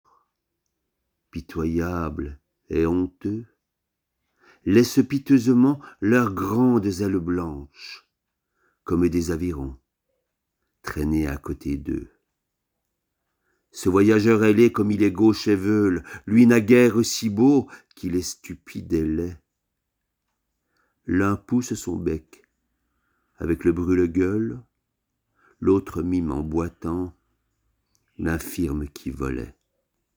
40 - 65 ans - Ténor